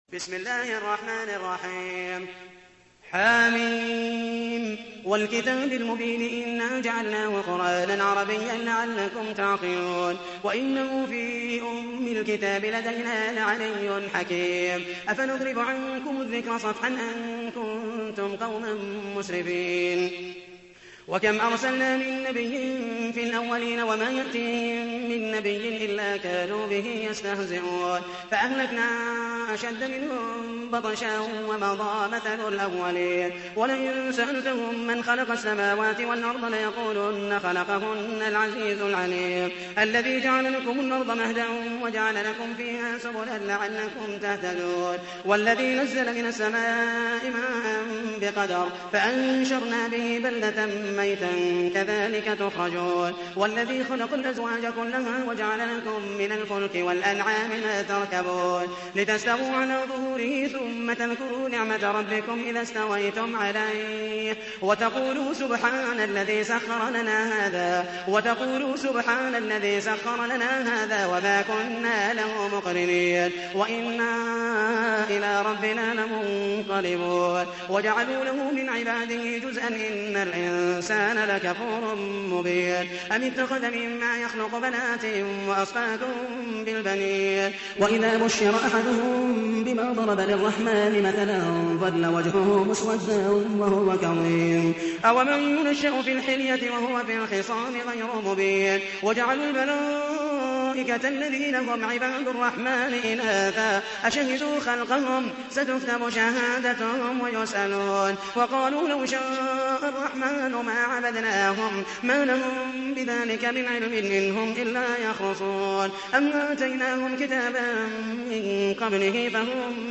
تحميل : 43. سورة الزخرف / القارئ محمد المحيسني / القرآن الكريم / موقع يا حسين